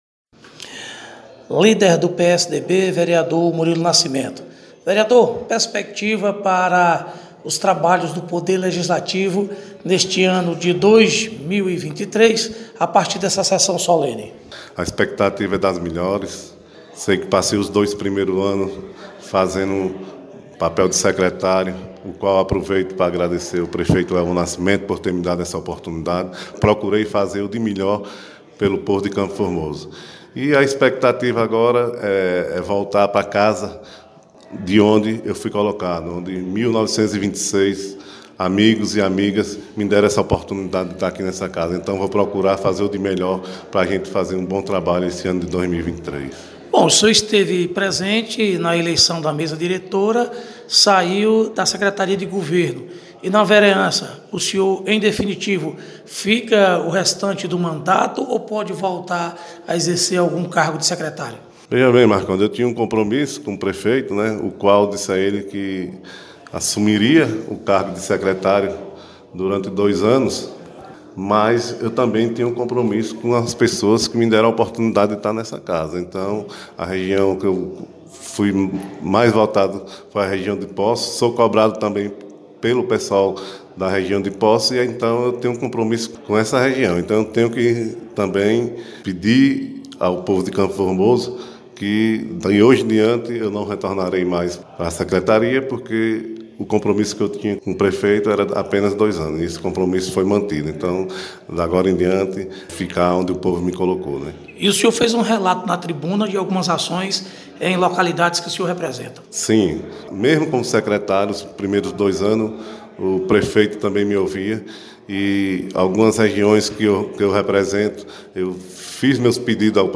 Reportagem: Vereadores de CF – Início dos trabalhos legislativos